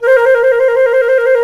Index of /90_sSampleCDs/Roland LCDP04 Orchestral Winds/FLT_C Flute FX/FLT_C Flt Trill